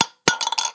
硬币掉入铁罐 " 硬币掉落8
描述：单枚硬币掉进一个罐子里